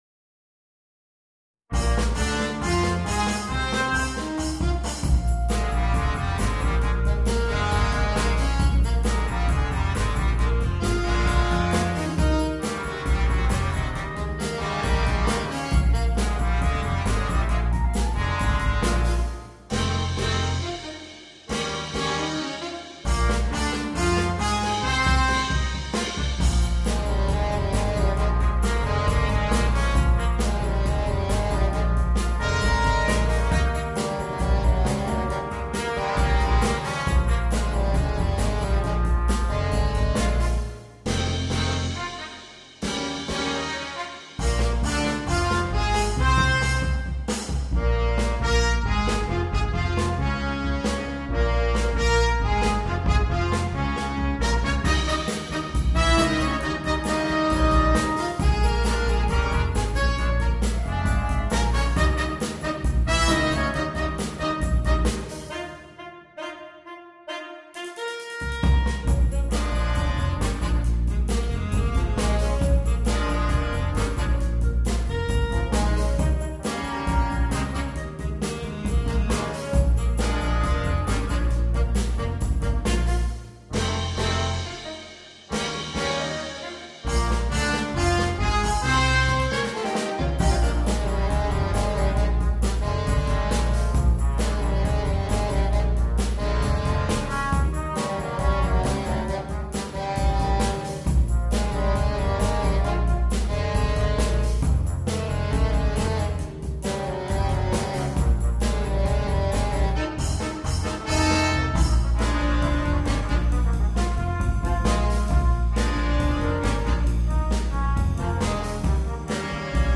для смешанного состава